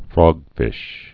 (frôgfĭsh, frŏg-)